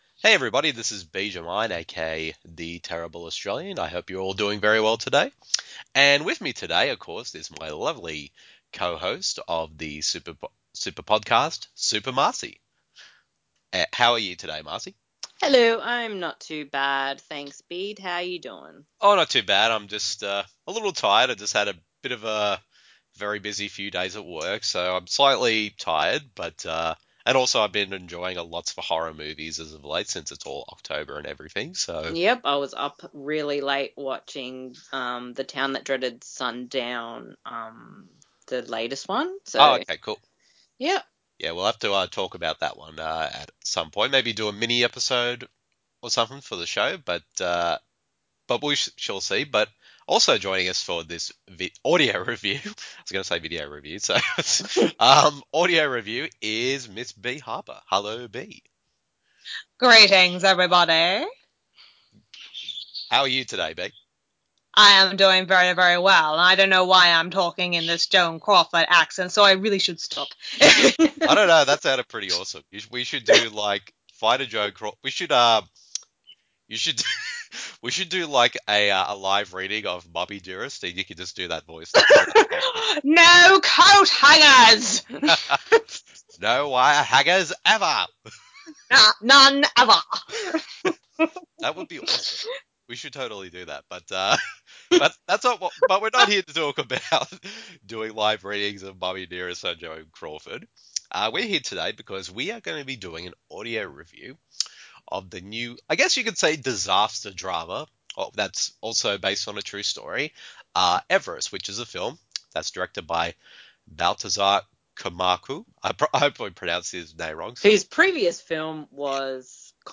[Audio Review] Everest
The following review in an audio format, as a back and forth discussion between the three of us.